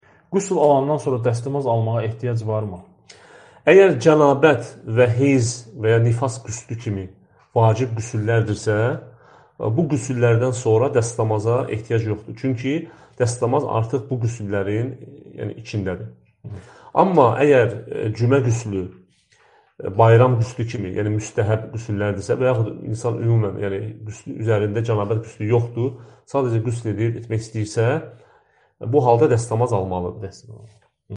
Sual-Cavab